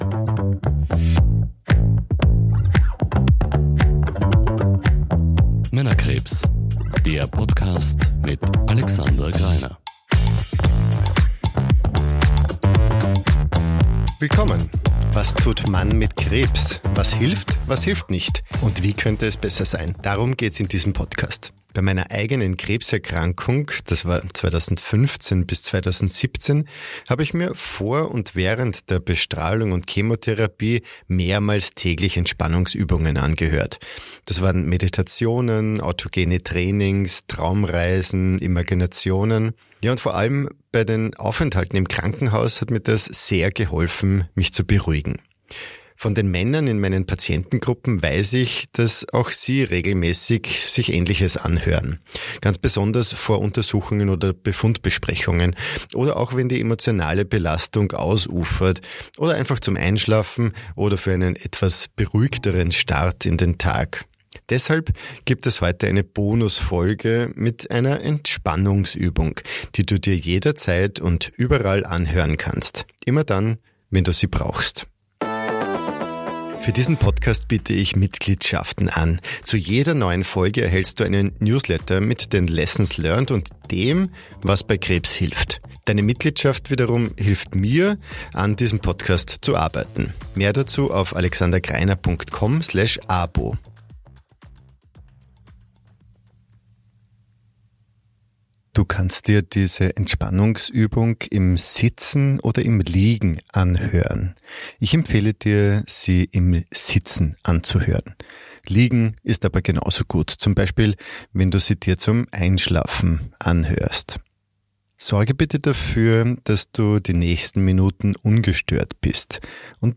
Entspannungsübung für den Alltag mit Krebs (Bodyscan und Atemmeditation) · Folge 7 ~ Männerkrebs – Was tut Mann mit Krebs?